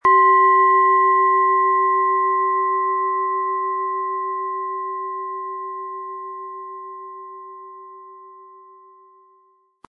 Planetenschale® Leicht Informationen aufnehmen & Gestärkt werden mit Biorhythmus Geist & Biorhythmus Körper, Ø 15,7 cm, 400-500 Gramm inkl. Klöppel
• Mittlerer Ton: Biorhythmus Körper
Aber dann würde der ungewöhnliche Ton und das einzigartige, bewegende Schwingen der traditionellen Herstellung fehlen.
PlanetentöneBiorythmus Geist & Biorhythmus Körper
MaterialBronze